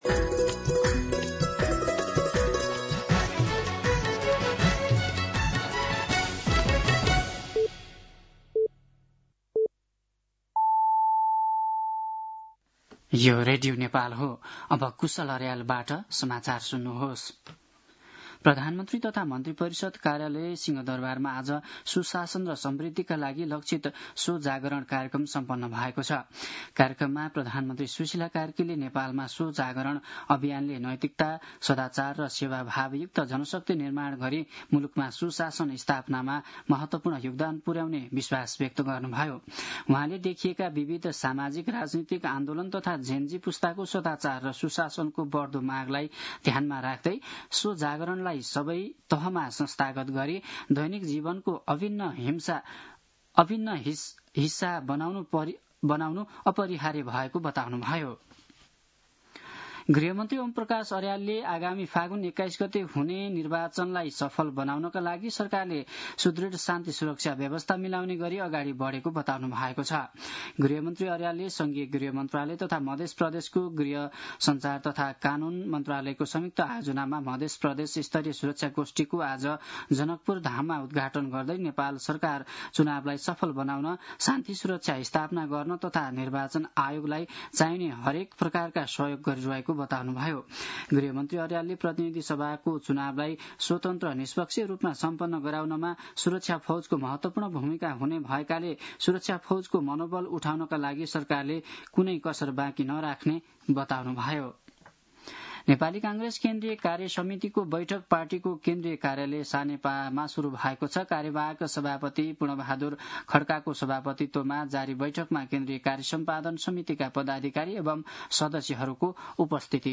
दिउँसो ४ बजेको नेपाली समाचार : ३० पुष , २०८२
4-pm-Nepali-News-3.mp3